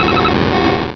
Cri de Qulbutoké dans Pokémon Rubis et Saphir.